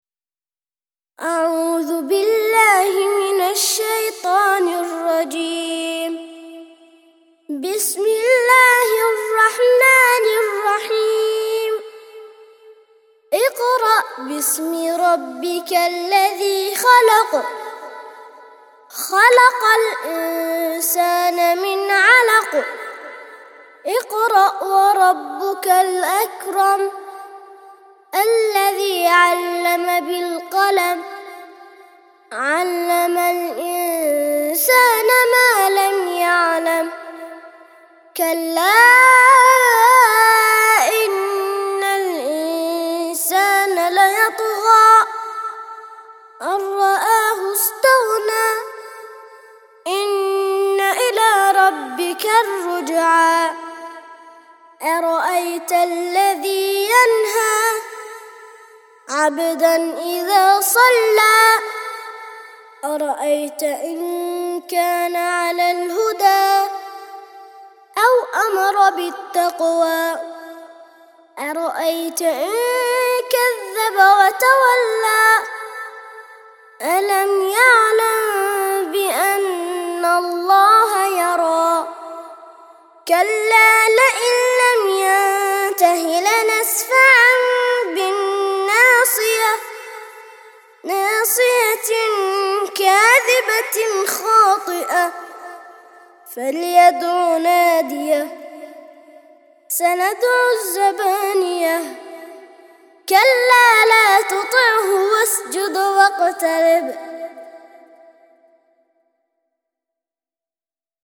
96- سورة العلق - ترتيل سورة العلق للأطفال لحفظ الملف في مجلد خاص اضغط بالزر الأيمن هنا ثم اختر (حفظ الهدف باسم - Save Target As) واختر المكان المناسب